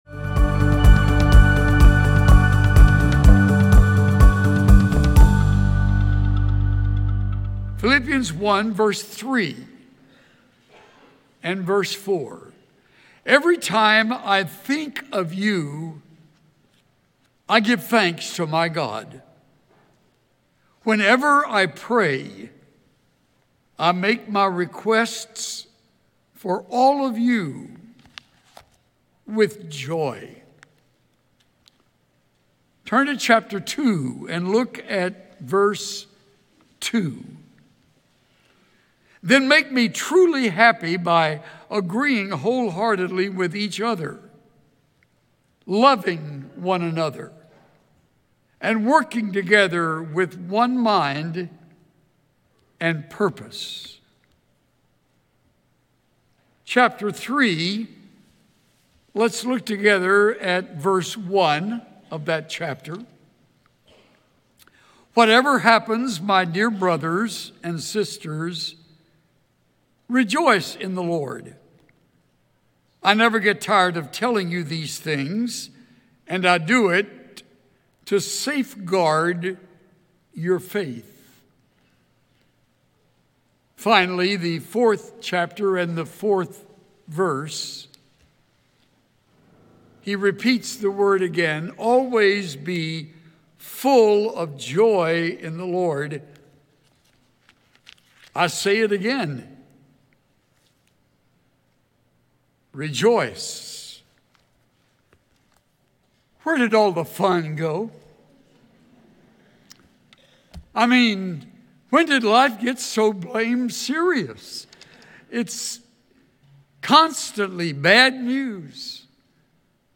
Let’s rejoice in the Lord together, for He is full of goodness and grace. Tune in for worship led by our Wind Symphony and the next message in Pastor Chuck Swindoll’s series on the Fruit of the Spirit.